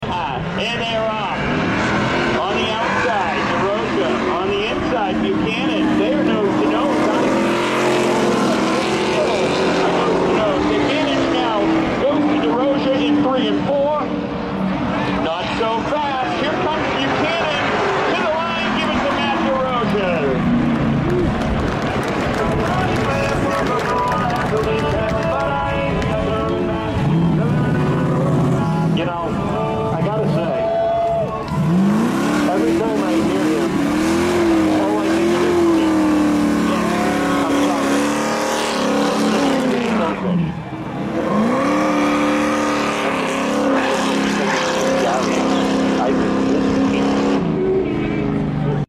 drags racing at season